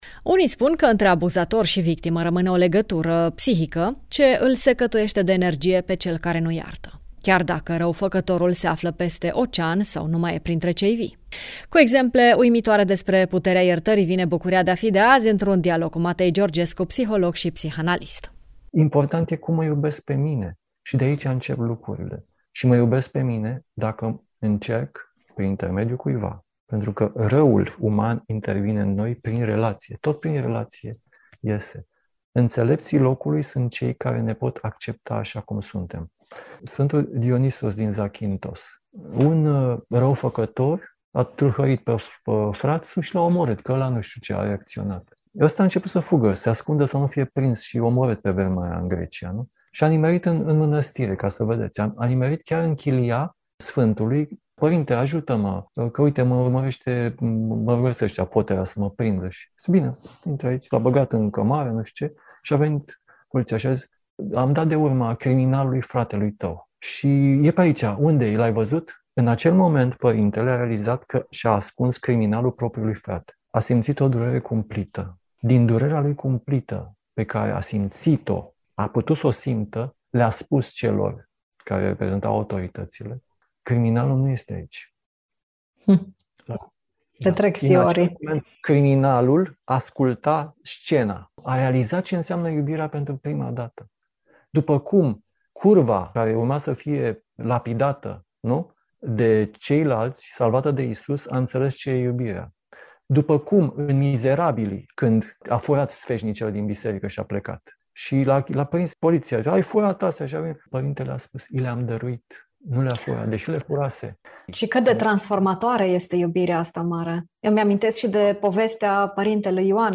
psiholog, psihanalist